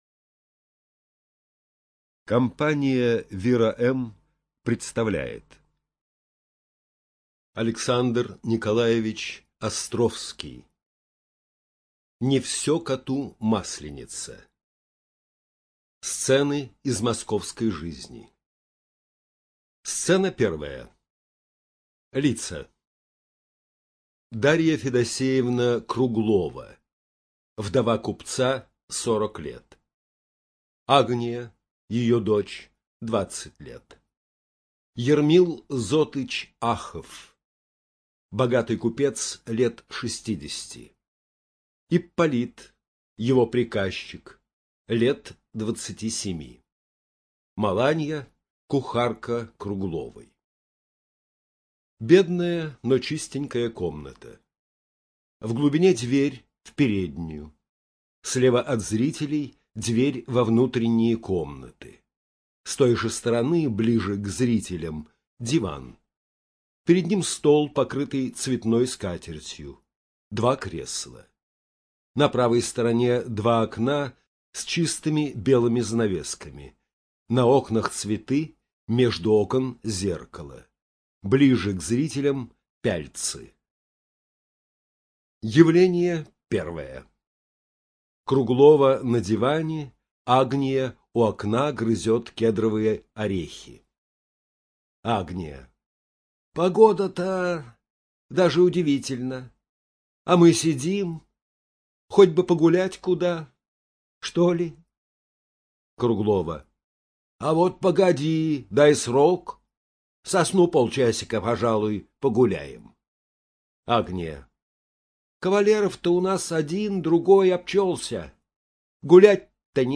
Студия звукозаписиВира-М